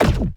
shieldHit.ogg